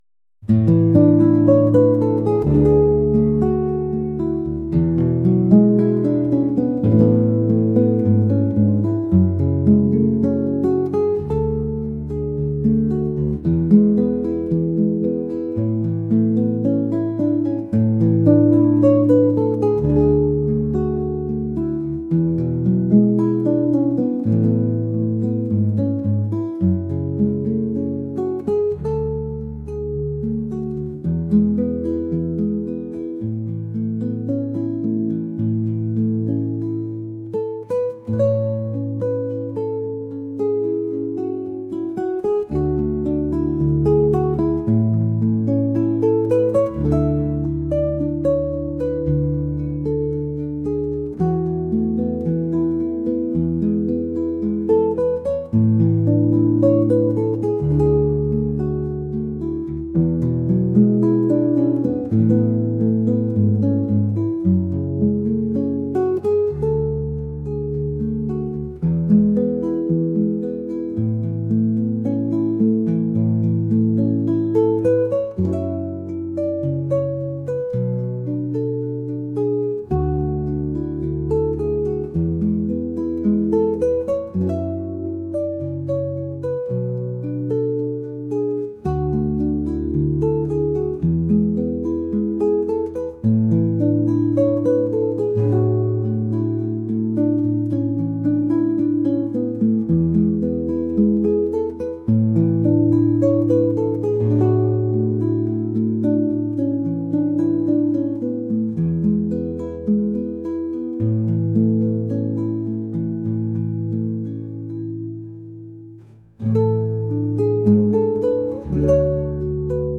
acoustic | folk | ambient